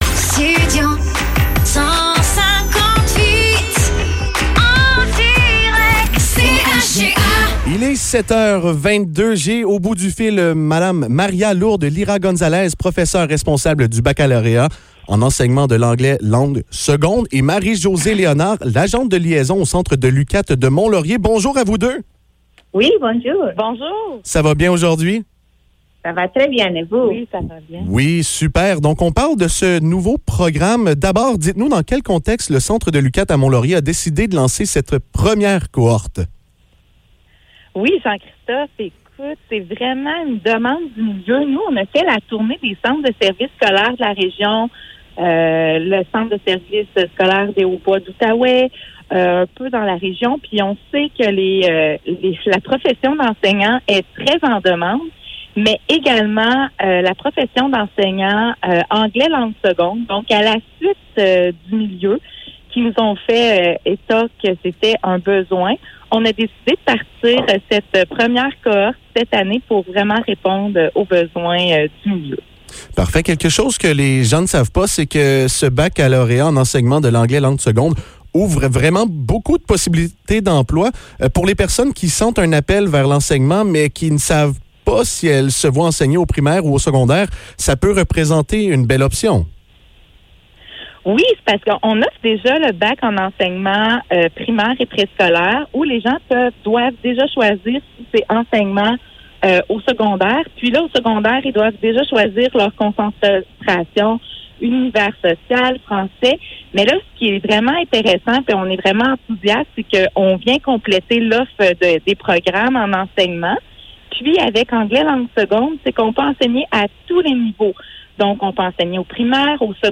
Entrevue sur le baccalauréat en enseignement de l'anglais langue seconde à l'UQAT
entrevue-sur-le-baccalaureat-en-enseignement-de-langlais-langue-seconde-a-luqat.mp3